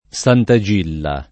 Santa Gilla [ S# nta J& lla ] top. (Sard.)